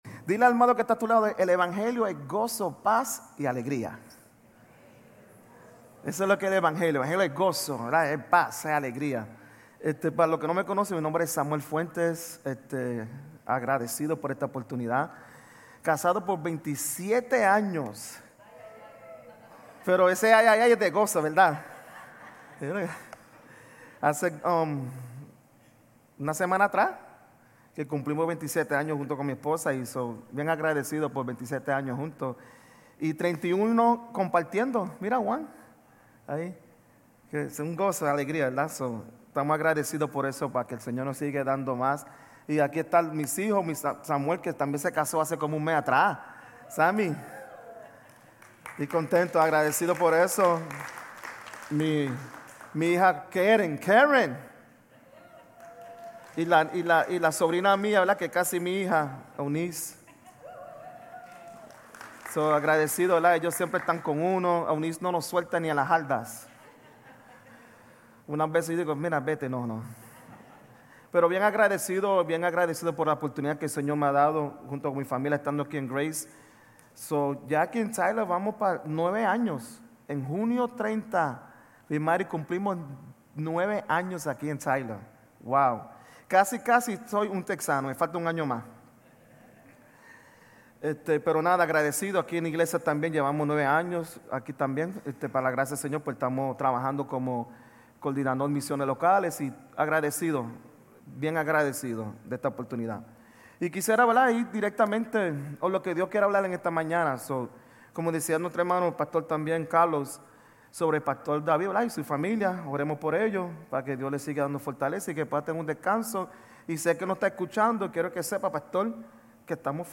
Sermones Grace Español 6_22 Grace Espanol Campus Jun 22 2025 | 00:36:32 1x 00:00 / 00:36:32 Subscribe Share RSS Feed Share Link Embed